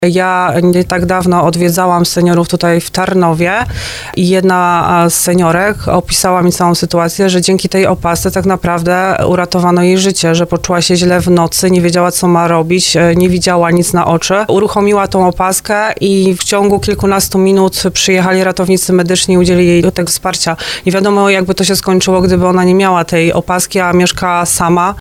Mówiła o tym w programie Słowo za Słowo Marta Malec-Lech z Zarządu Województwa Małopolskiego.